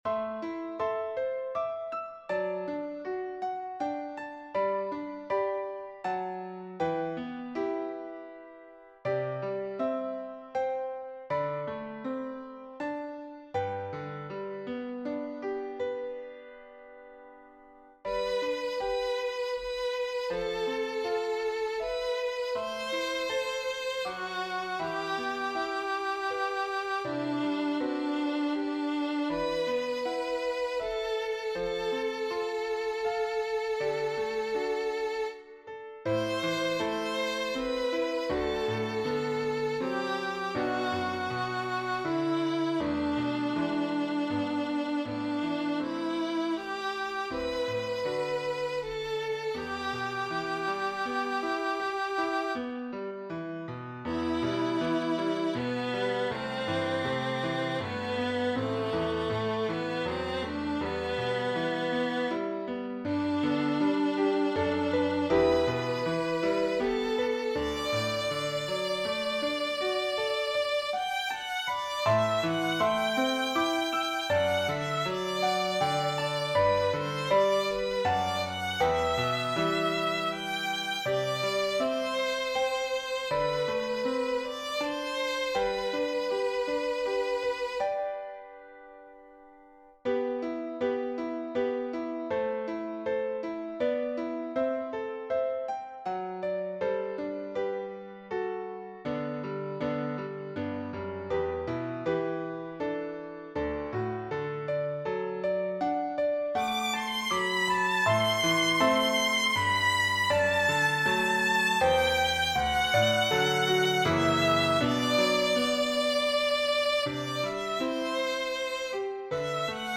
Voicing/Instrumentation: Piano Prelude/Postlude , Piano Solo